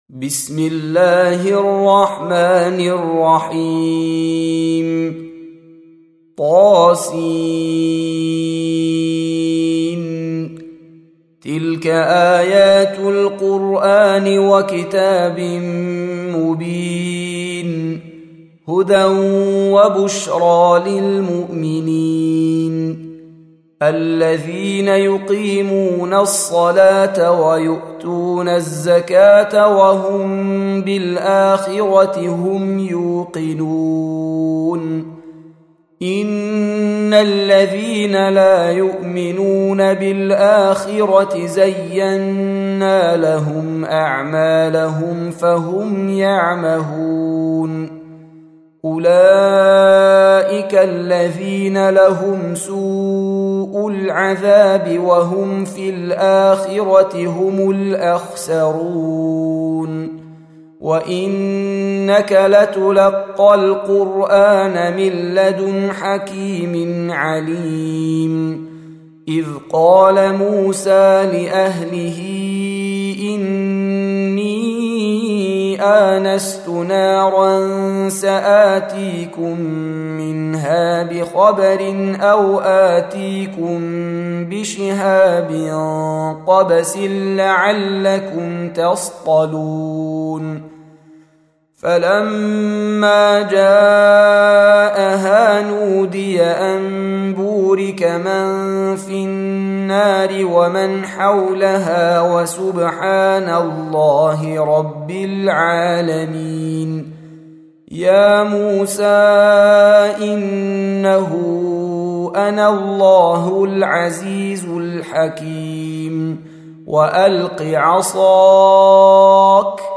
Surah Sequence تتابع السورة Download Surah حمّل السورة Reciting Murattalah Audio for 27. Surah An-Naml سورة النّمل N.B *Surah Includes Al-Basmalah Reciters Sequents تتابع التلاوات Reciters Repeats تكرار التلاوات